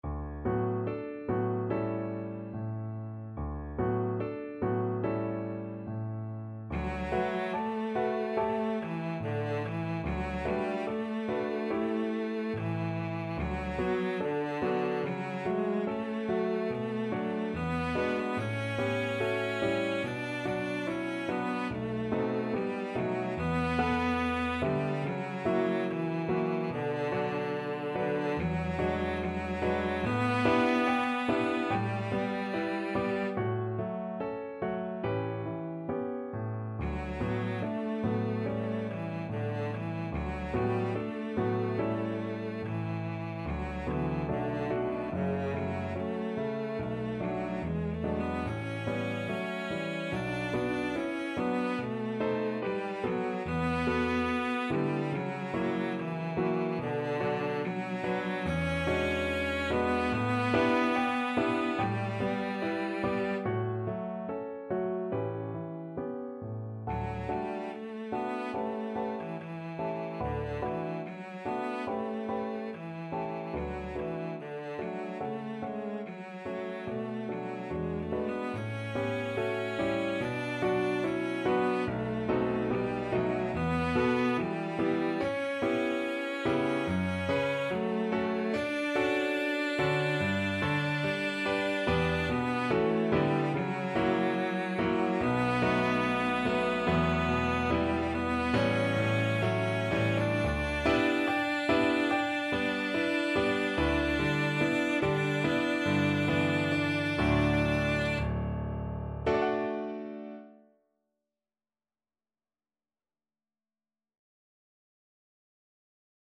4/4 (View more 4/4 Music)
~ = 72 In moderate time
Classical (View more Classical Cello Music)